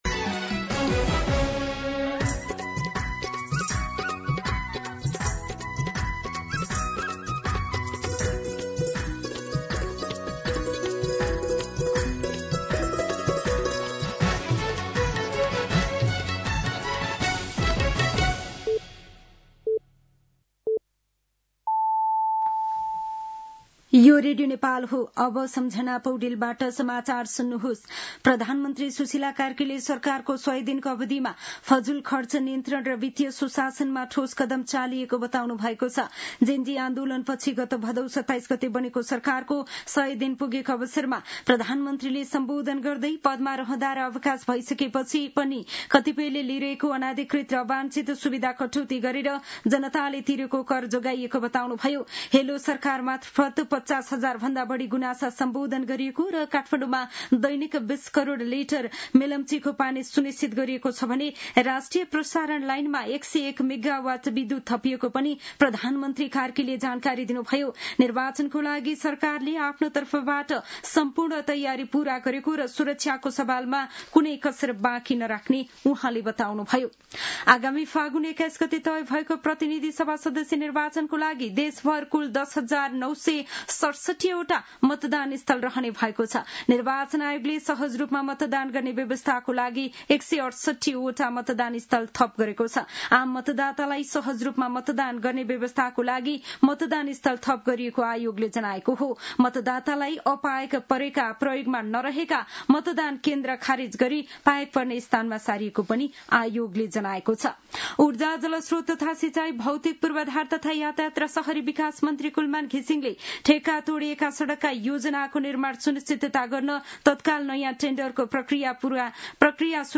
दिउँसो ४ बजेको नेपाली समाचार : ५ पुष , २०८२